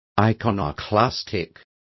Complete with pronunciation of the translation of iconoclastic.